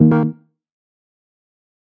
В коллекции представлены различные варианты предупреждающих сигналов, блокировок и системных оповещений.
Звук ошибочного ввода кода, сбой доступа